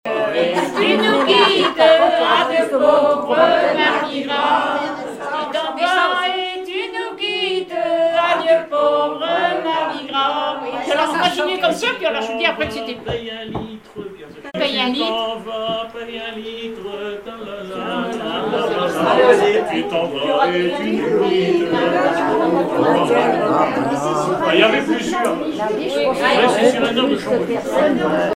Informateur(s) Club d'anciens de Saint-Pierre association
circonstance : carnaval, mardi-gras
Catégorie Pièce musicale inédite